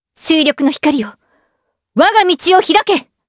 Chrisha-Vox_Skill3_jp.wav